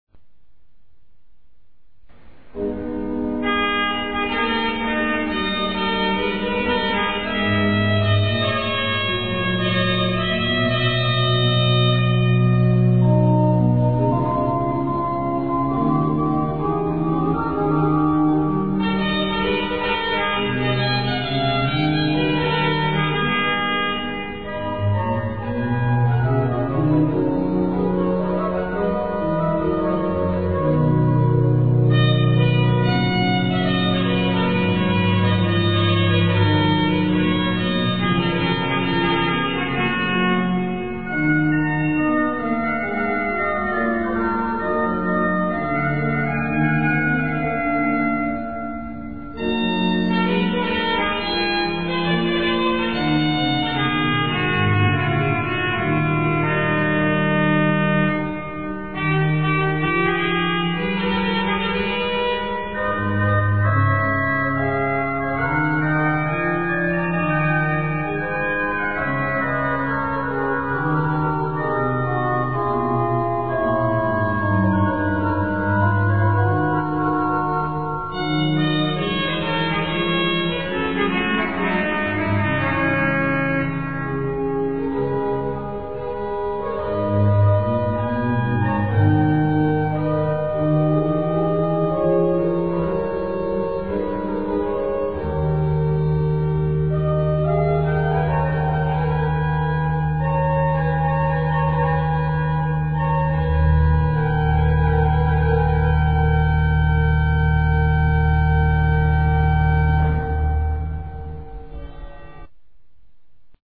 FILES AUDIO DAL VIVO
(organo della chiesa di Saint Martin a Dieppe - Normandia - Francia)
organo